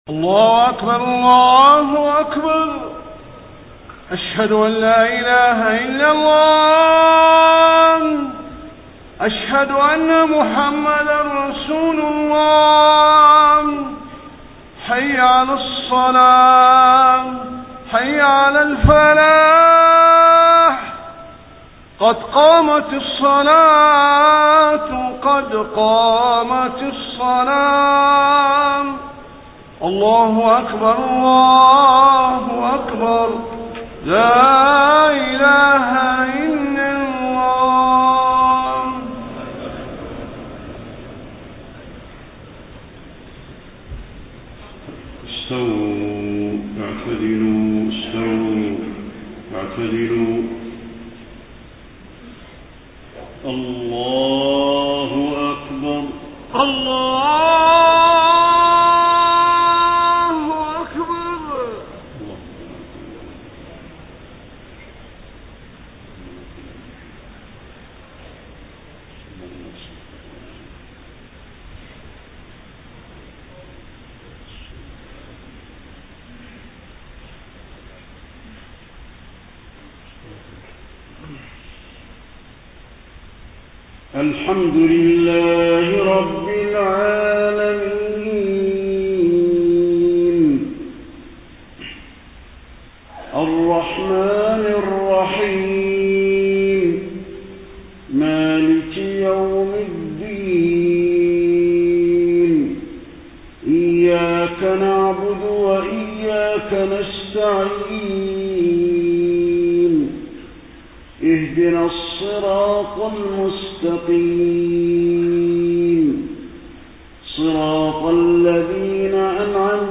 صلاة العشاء 6 ربيع الأول 1431هـ خواتيم سورتي الكهف 107-110 و مريم 96-98 > 1431 🕌 > الفروض - تلاوات الحرمين